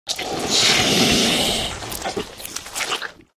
fracture_eat_1.ogg